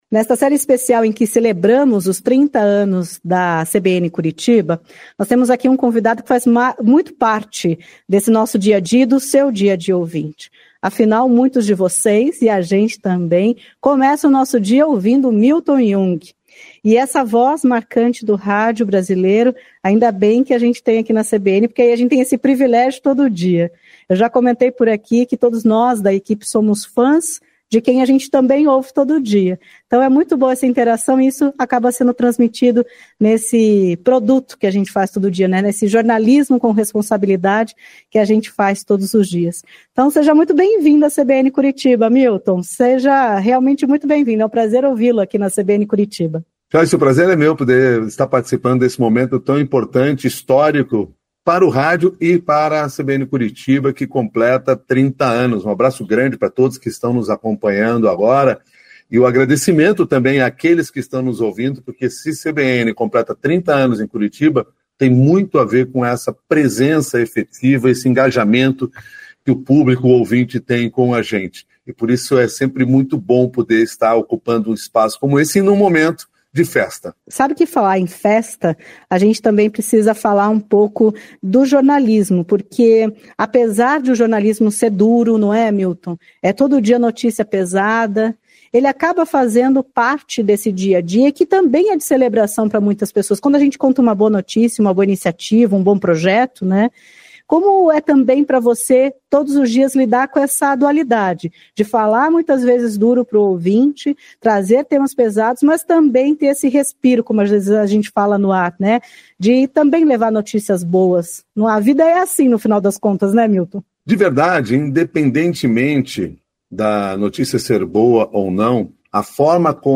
Mílton Jung fala sobre o papel importante do radiojornalismo em entrevista à CBN Curitiba
A conversa desta terça-feira (06) é com Mílton Jung.